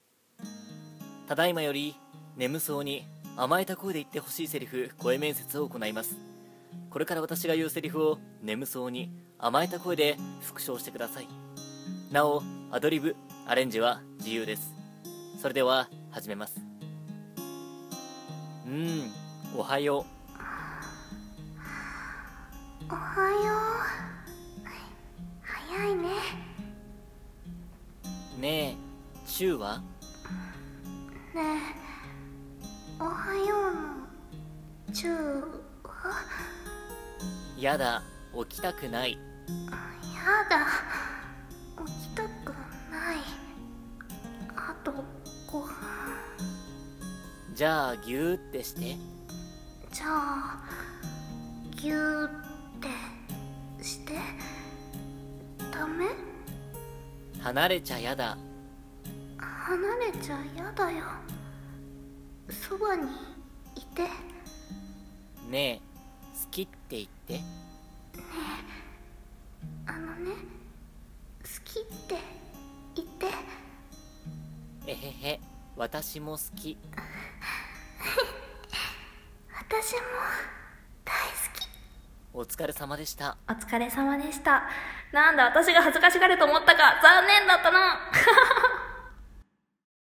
【悶絶級】眠そうに甘えた声で言って欲しいセリフ声面接【照れたら負け】